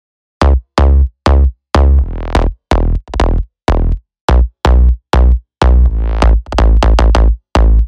Inside, you’ll find all of the essentials from punchy basses, powerful drones, lush pads, and much more.